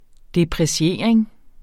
Udtale [ depʁeˈɕeɐ̯ˀeŋ ]